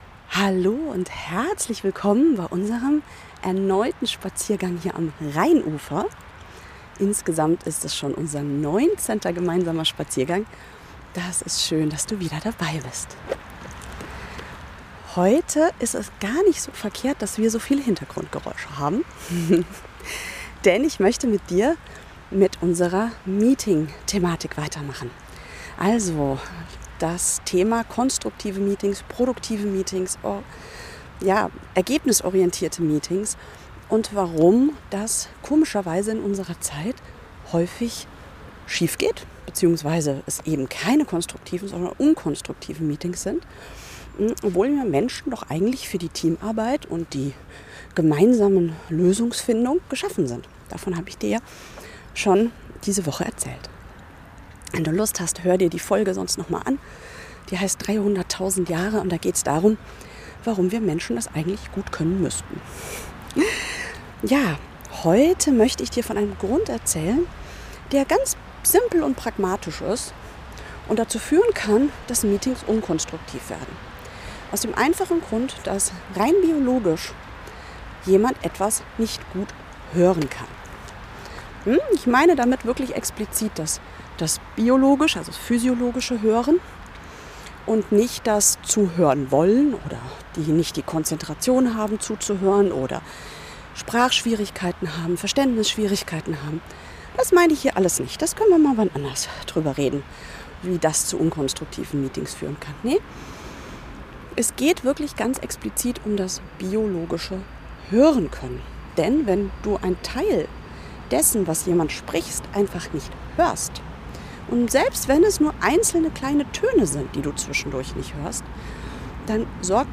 Hört mich der Kollege im Meeting einfach nicht? Ich stehe hier am Rhein mit ziemlich vielen Hintergrundgeräuschen und muss dir ehrlich sagen: Das ist für mich total schwierig zu verstehen, wenn jemand spricht!